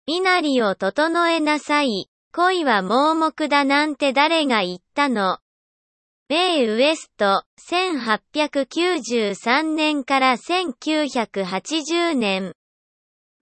(Google Translateのエンジンを使用した、Sound of Textによるテキスト読み上げ)